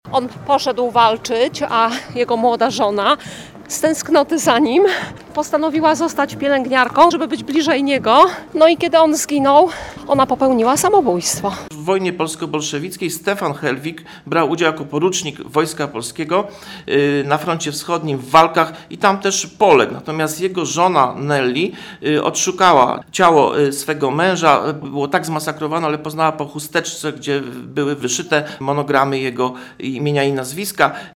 Kiedy on zginął, ona popełniła samobójstwo – wspomina jedna z mieszkanek Bełchatowa.